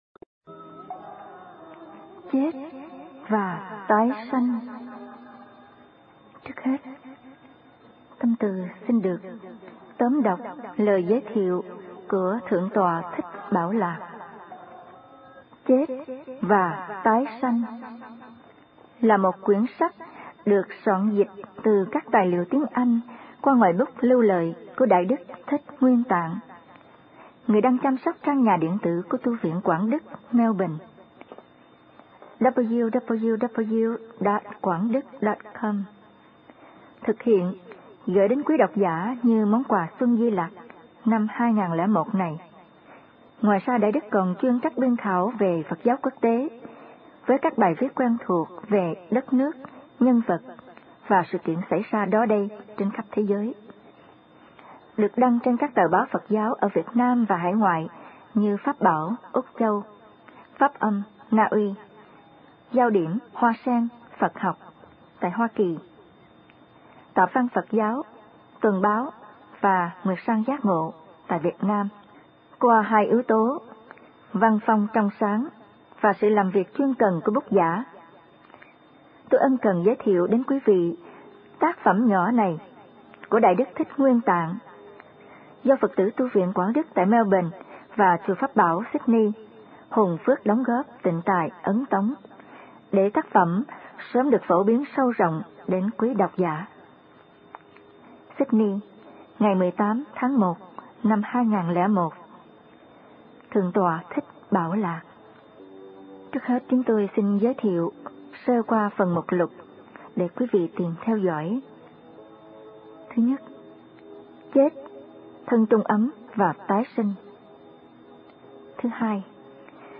(Audio book) Chết và Tái Sanh